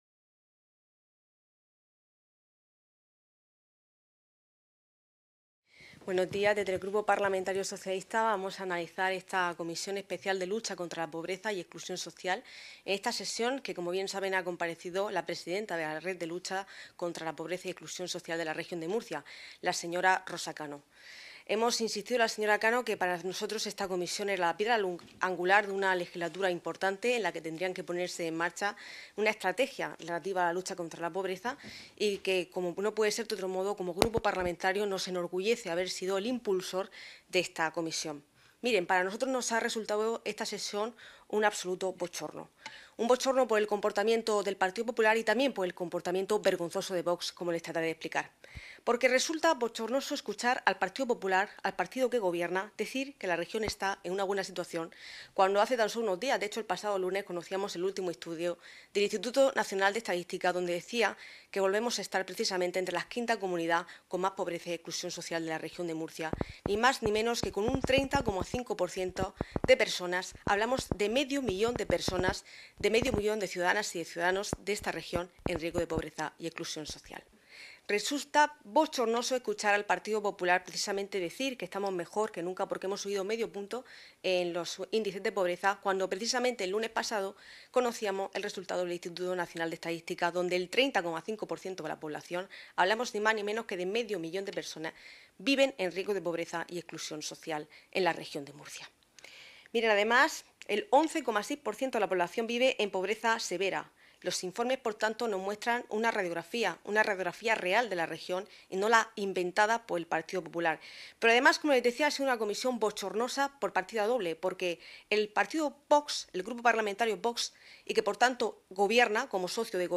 Rueda de prensa tras la Comisión Especial de Estudio de Lucha contra la Pobreza y la Exclusión Social en la Región de Murcia
• Grupo Parlamentario Socialista